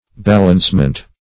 Search Result for " balancement" : The Collaborative International Dictionary of English v.0.48: Balancement \Bal"ance*ment\, n. The act or result of balancing or adjusting; equipoise; even adjustment of forces.